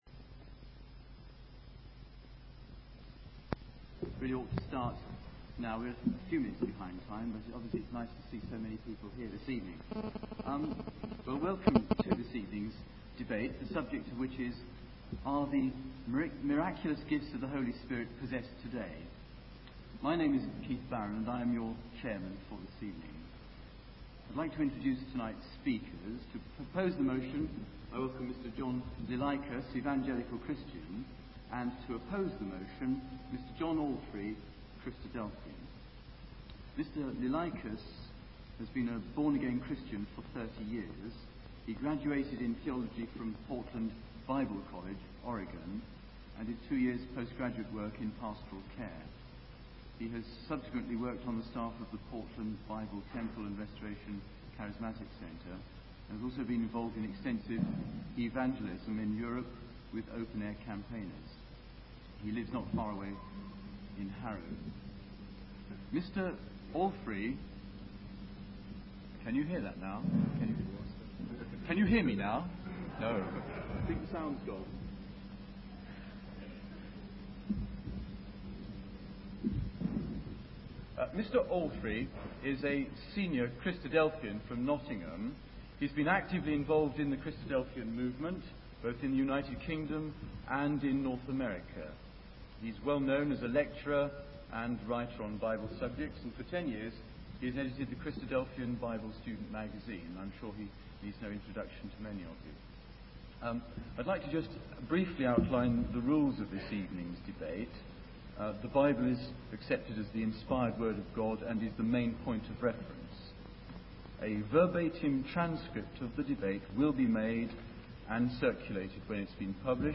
Debate Are Miraculous gifts of the Holy spirit possesed today.mp3